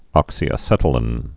(ŏksē-ə-sĕtl-ĭn, -ēn)